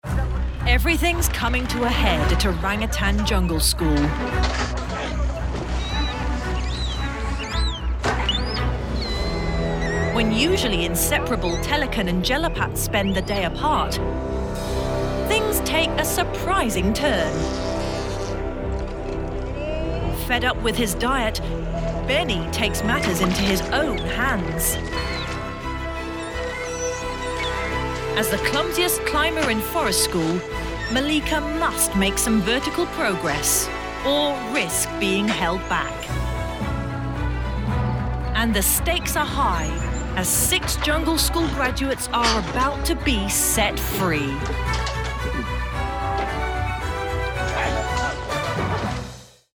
Narration for "Orangutan Jungle School"
English - British RP
Middle Aged